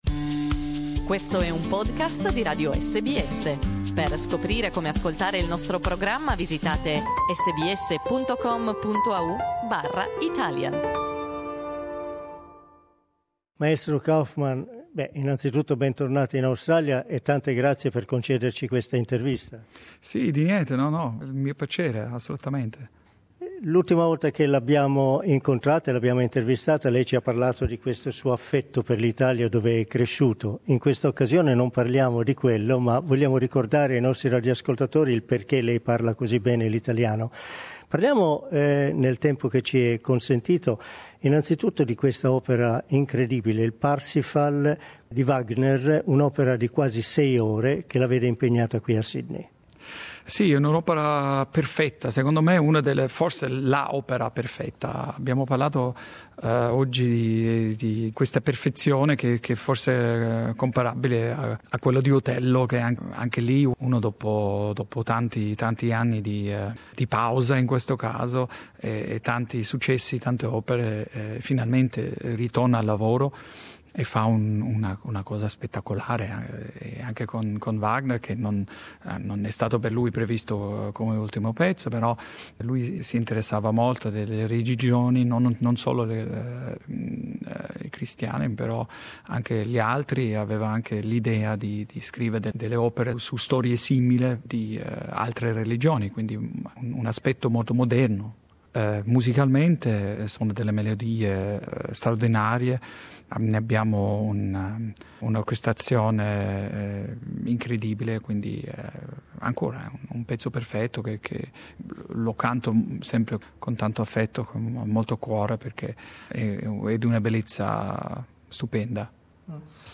Una conversazione con Jonas Kaufmann.